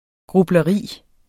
Udtale [ gʁublʌˈʁiˀ ]